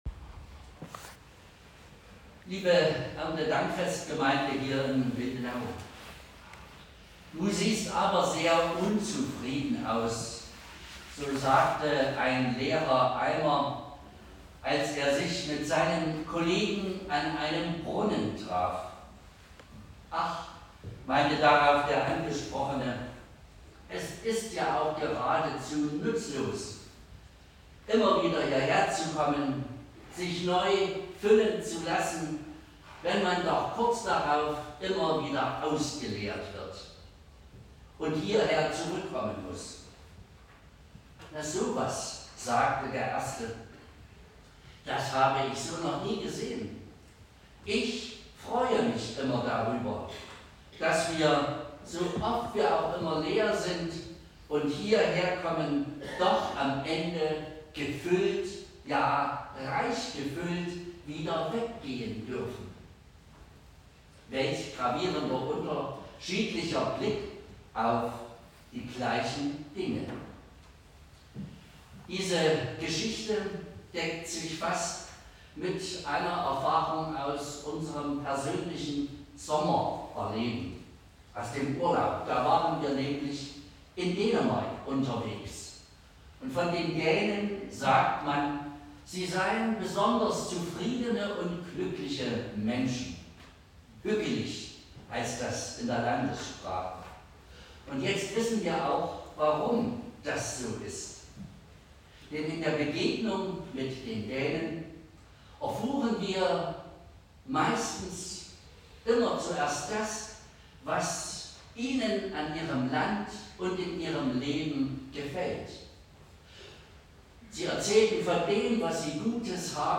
Passage: Psalm 103;1-2 Gottesdienstart: Erntedankgottesdienst Leider wurde letzte Woche die falsche Datei hochgeladen.